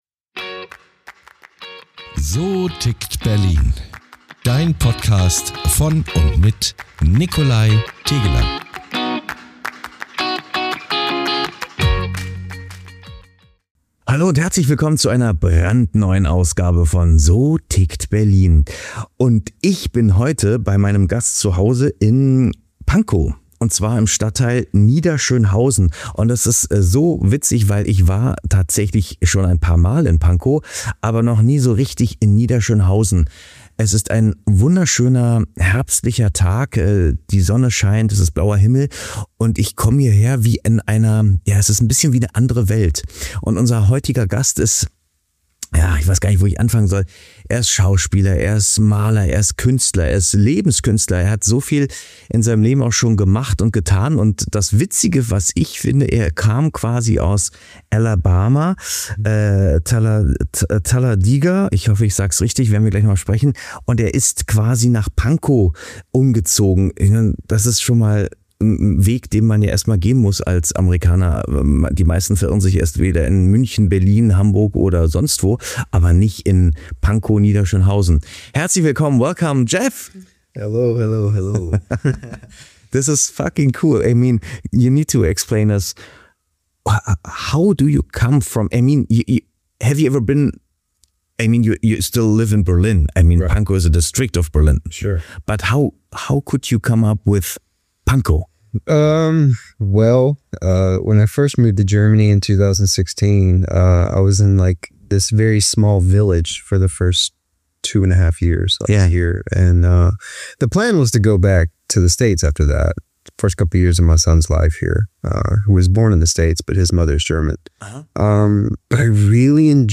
Ein Gespräch über Mut, Transformation und die Sprache der Farben und Emotionen.